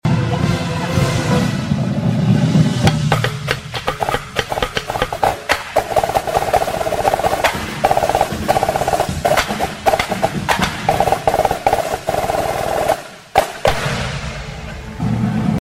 The Worlds most famous Snare sound effects free download
The Worlds most famous Snare Break (Bluecoats 2016)